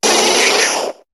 Cri de Coconfort dans Pokémon HOME.